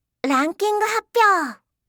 声素材-数字・時間読み上げ
明るく聴き取りやすい女の子声